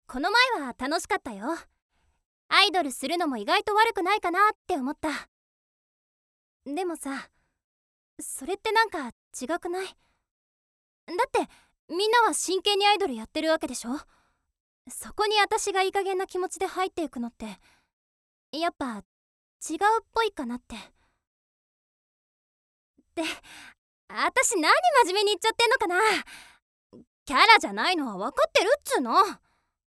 일본의 여성 성우.
보이스 샘플